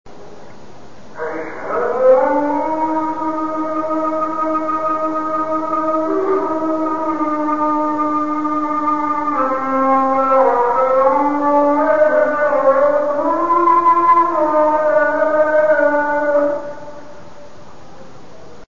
I recorded a Muslim guy praying at about 5 in the morning. I stuck my recorder out the window of my room at Hotel Govardhan and snagged some of his amplified magic.
There is a short version, with one brain-splitting burst and a longer one that goes on for about 2 1/2 minutes.
morning_muslim.1.mp3